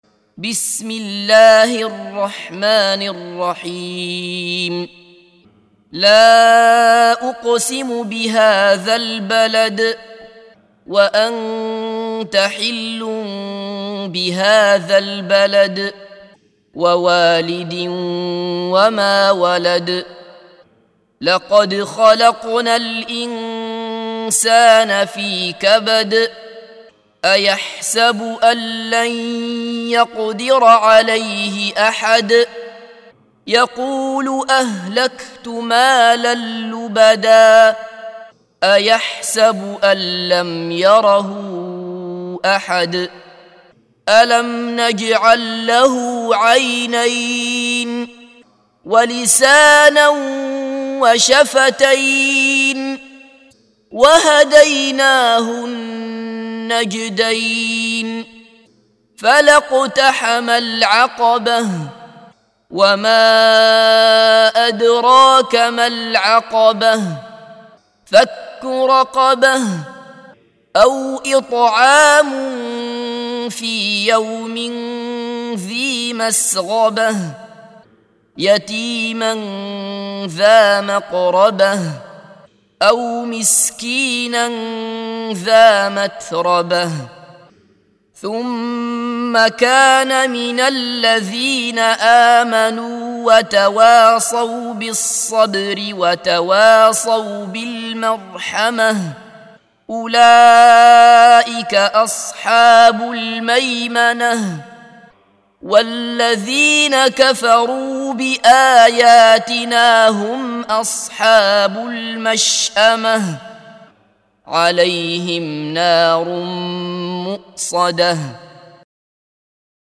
سُورَةُ البَلَدِ بصوت الشيخ عبدالله بصفر